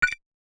Simple Cute Alert 19.wav